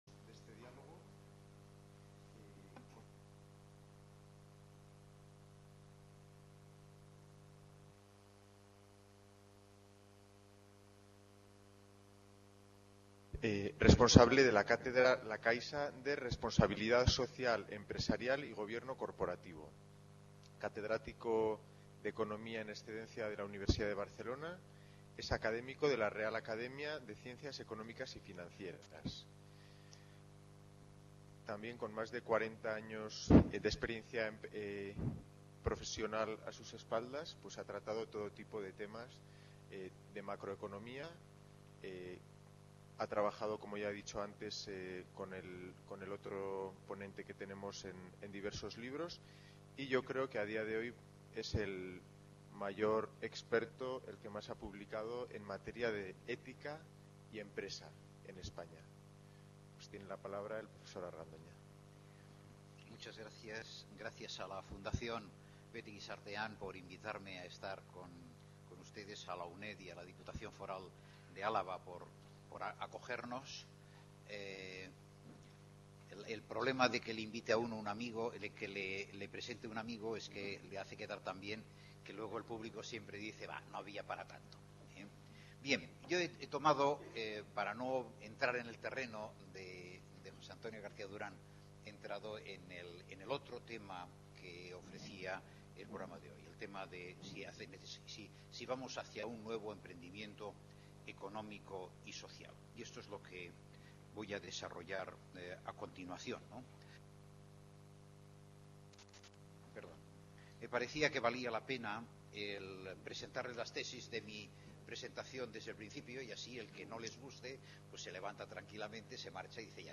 Hacia un emprendimiento ético social” | Red: UNED | Centro: UNED | Asig: Reunion, debate, coloquio...